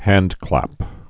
(hăndklăp)